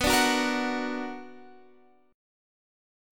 BmM7 chord